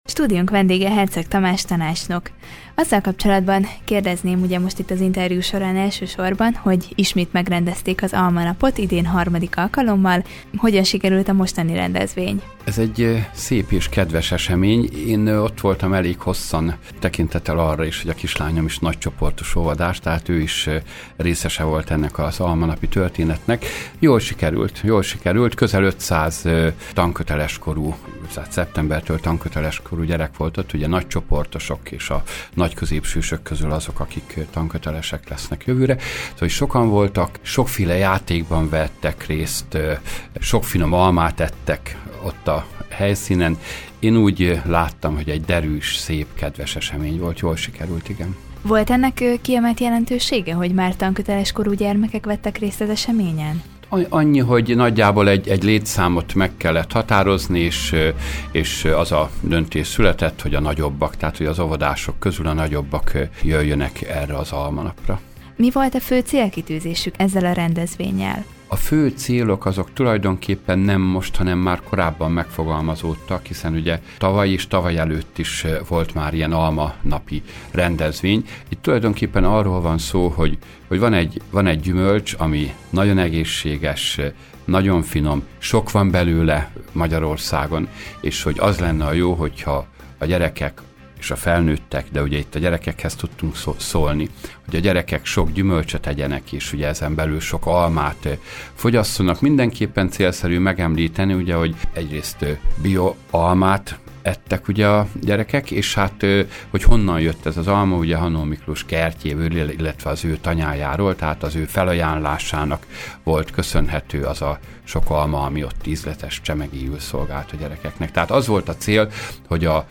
Herczeg Tamás, békéscsabai önkormányzati képviselő, tanácsnok volt a Körös Hírcentrum stúdiójának vendége. Vele beszélgetett tudósítónk az óvodások számára szervezett Almanap múltjáról és jelenéről.